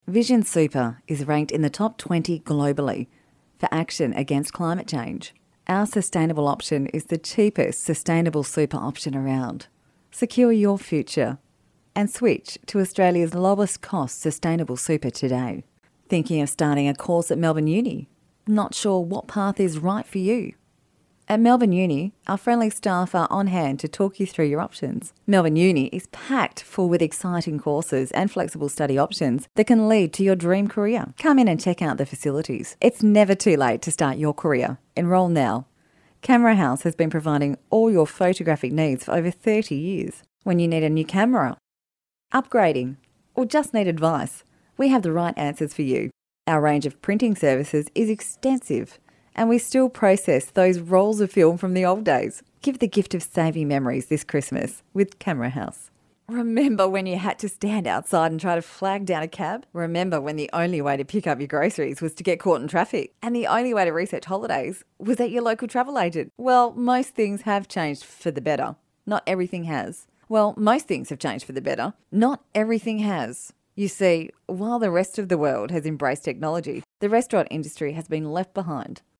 englisch (australisch)
Sprechprobe: eLearning (Muttersprache):
I have a versatile voice with ability to sound young and funky or older and mature, being able to adapt styles of read and tones to suit the project. A warm, depth that engages and cuts through.
natural dry commercial reads .mp3